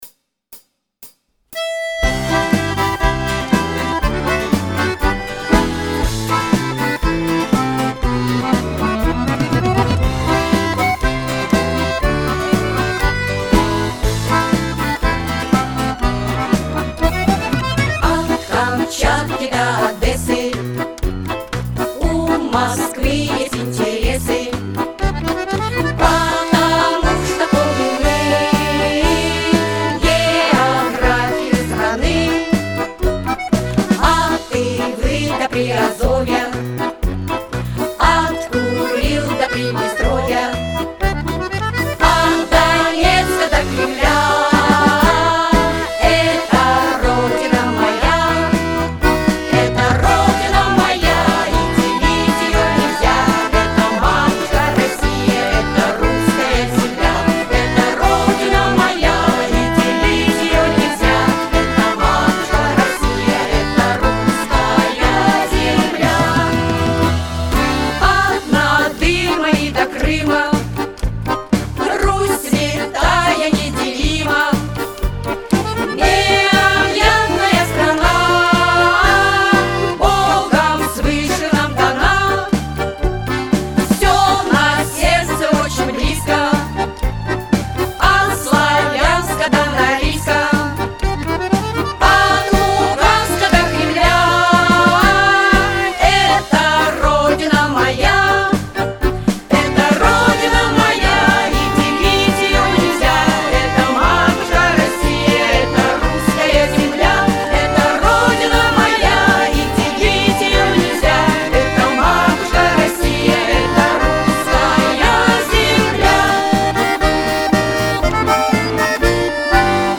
По заказу вокального коллектива.